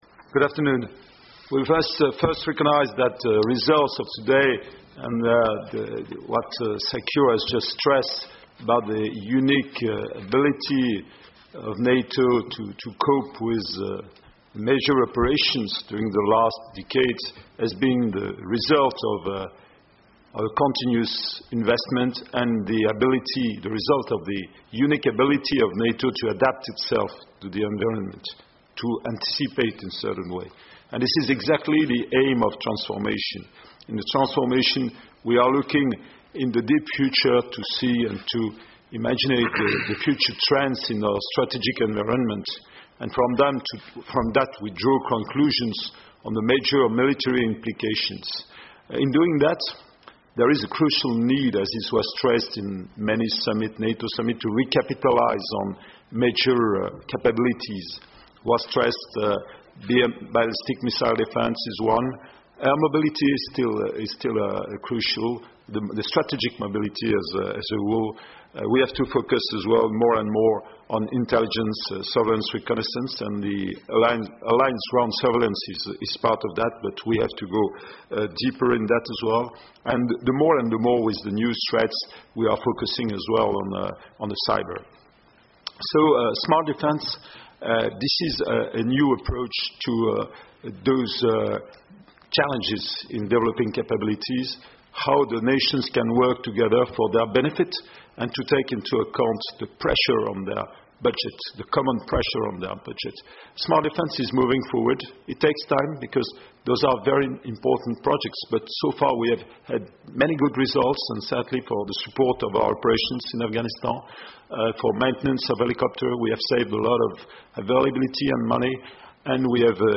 Opening statement by the Supreme Allied Commander Transformation,General Jean-Paul Paloméros, at the joint press point following the 170th NATO Chiefs of Defence meeting